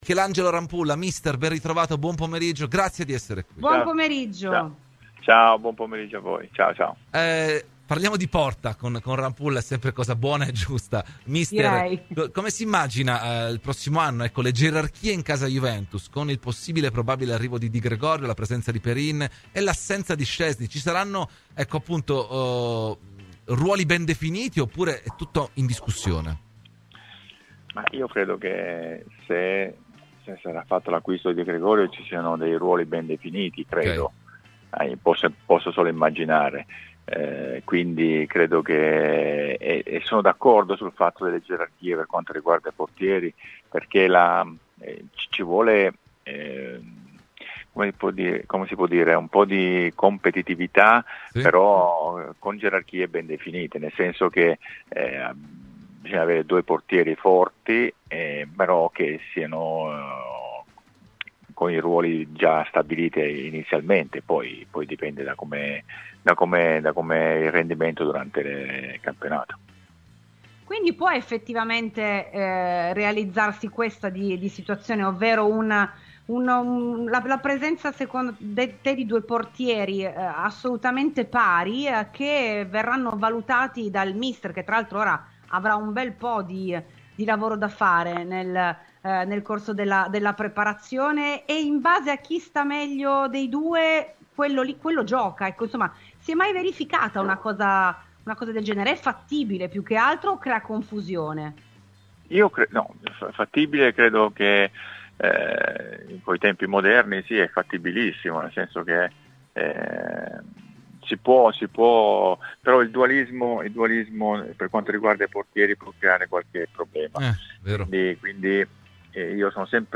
Ospite di "Terzo Tempo" su Radio Bianconera, l'ex numero 12 della Juventus Michelangelo Rampulla ha parlato di quelle che dovrebbero essere le gerarchie in porta in casa Juventus con l'uscita di Szczesny e l'arrivo di Di Gregorio dal Monza: "Io credo che se arriverà Di Gregorio i ruoli saranno ben definiti e sono d'accordo con l'idea di imporre gerarchie nette per quanto riguarda i portieri perchè il dualismo tra i pali può creare dei problemi.